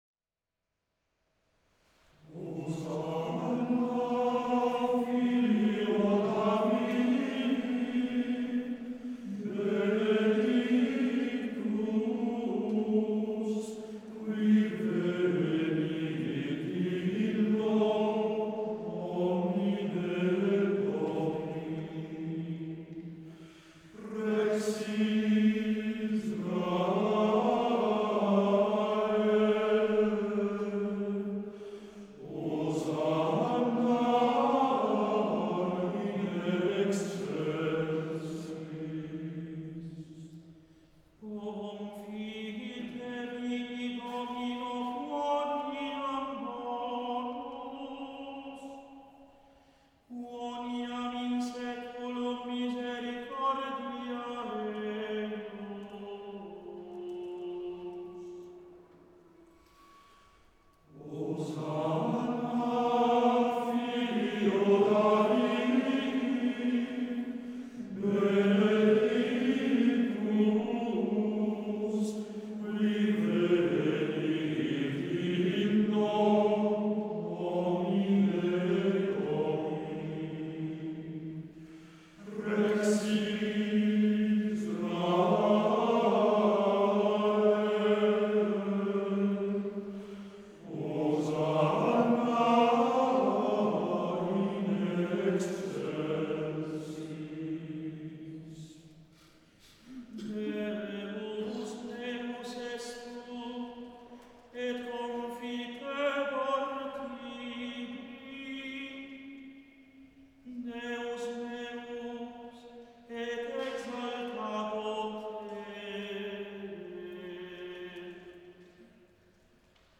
Elevazione Musicale Santa Pasqua 2025.
Basilica di S.Alessandro in Colonna, Bergamo
Canto gregoriano